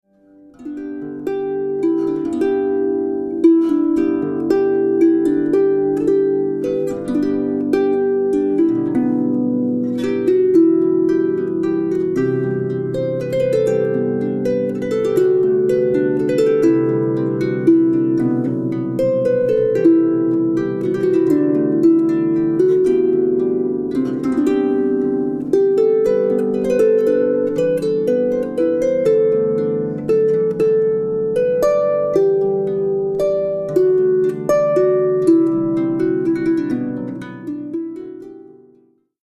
classic Irish songs and melodies